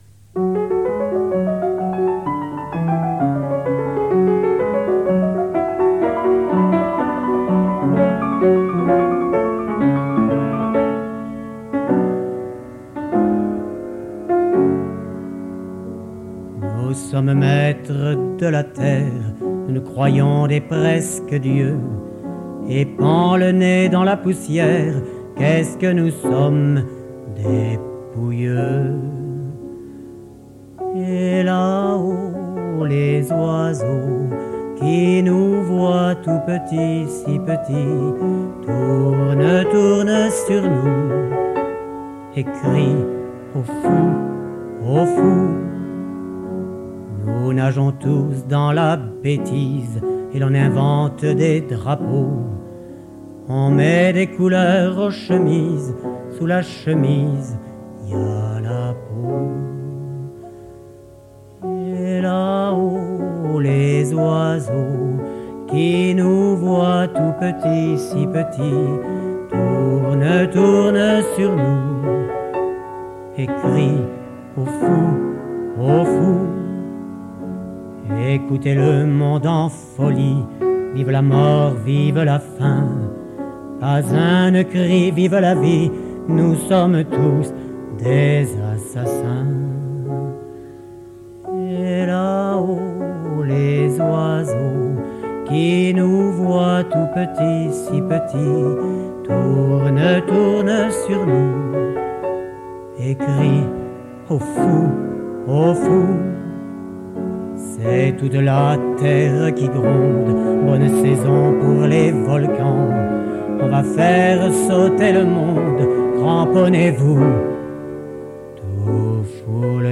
Enregistr�e en public en 1985
Cette chanson r�aliste